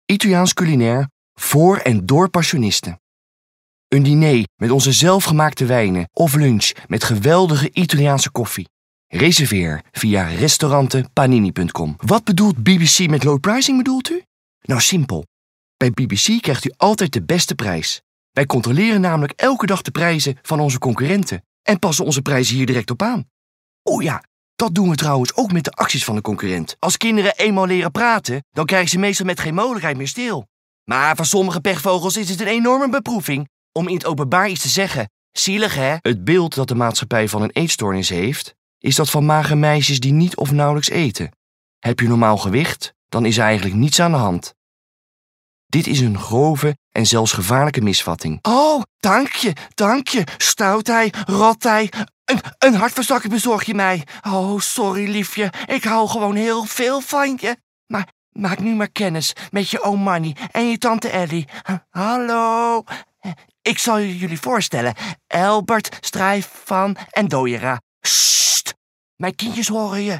Showreel Showreel Stemdemo luisteren Showreel.